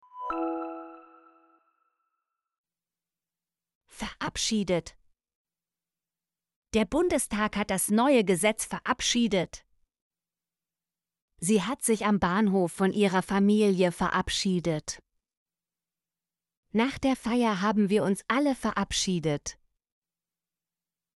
verabschiedet - Example Sentences & Pronunciation, German Frequency List